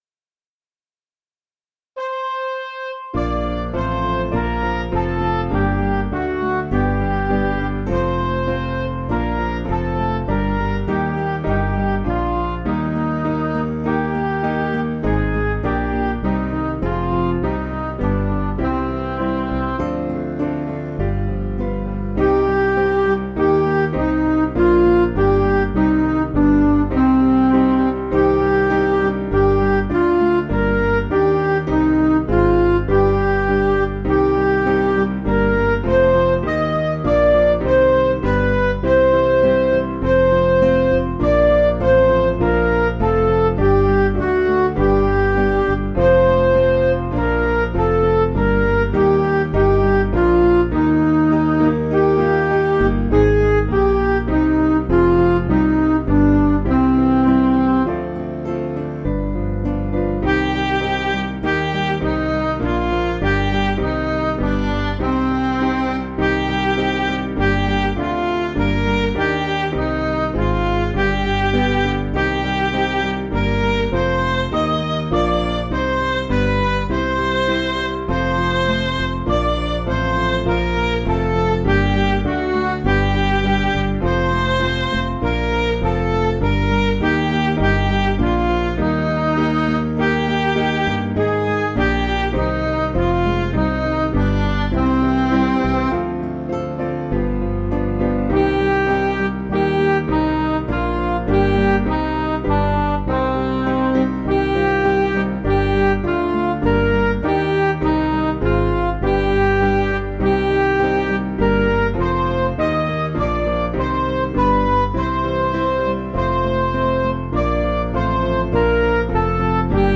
Piano & Instrumental
(CM)   9/Cm
Midi